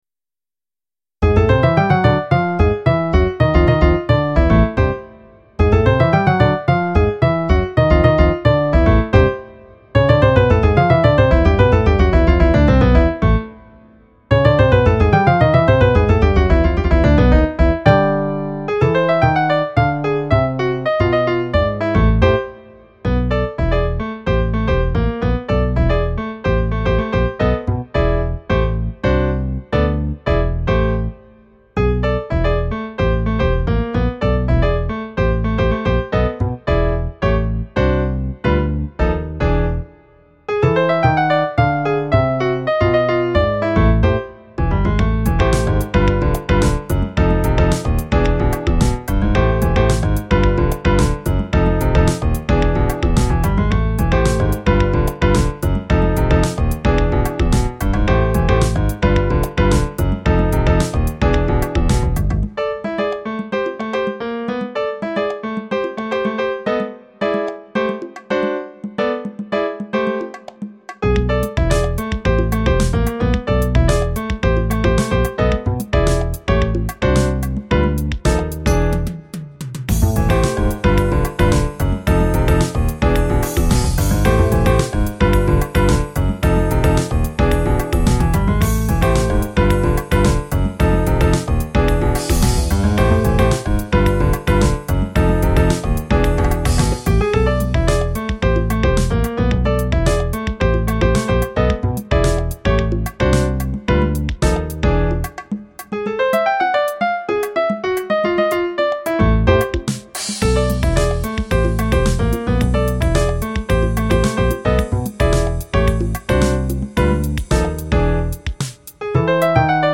Style JAZZ